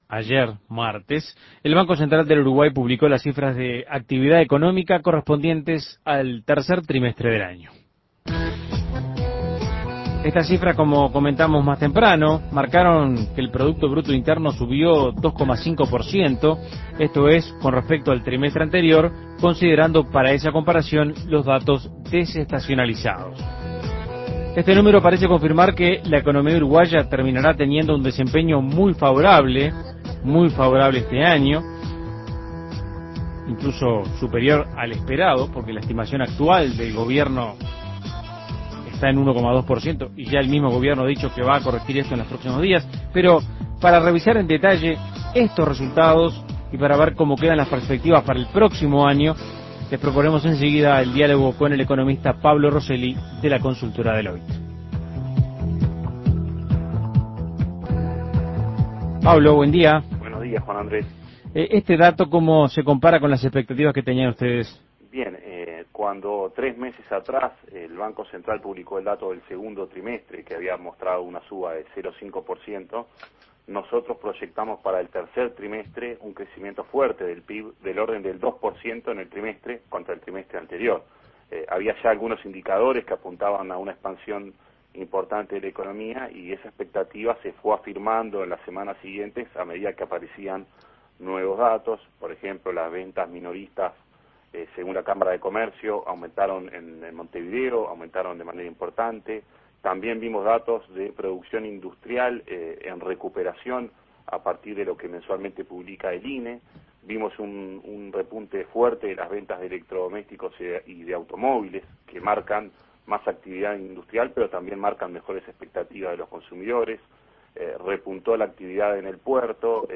Análisis Económico El PBI uruguayo subió 2,5% en relación al trimestre anterior, ¿cómo quedan las perspectivas para el año próximo?